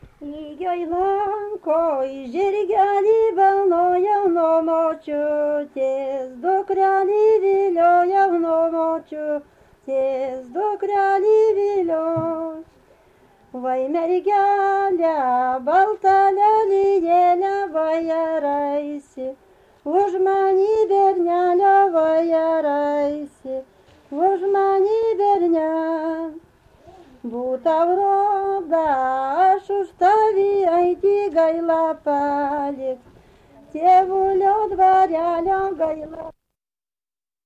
Subject smulkieji žanrai
Erdvinė aprėptis Druskininkai
Atlikimo pubūdis vokalinis